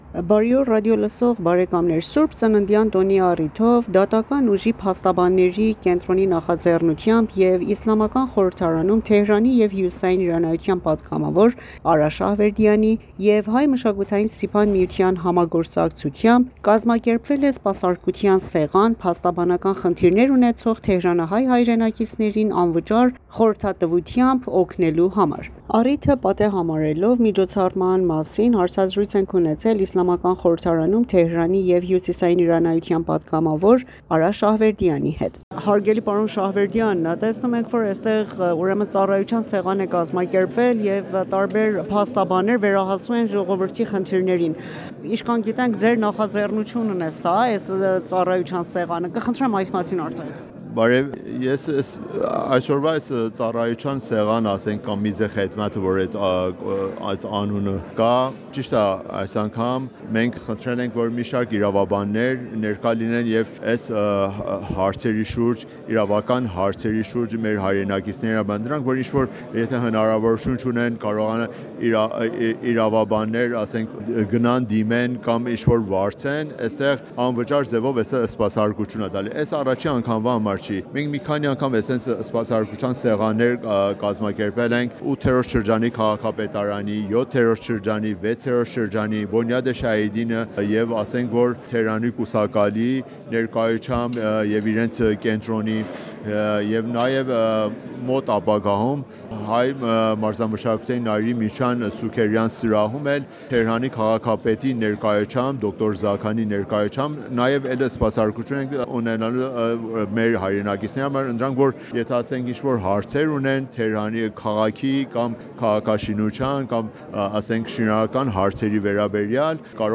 Միջոցառման նպատակն էր փաստաբանական խնդիրներ ունեցող թեհրանահայ հայրենակիցներին անվճար խորհրդատվությամբ օգնել:Այս մասին հարցազրույց ենք ունեցել պատգամավոր Արա Շահվերդյանի հետ,որը ներկայացնում ենք ձեր ուշադրությանը։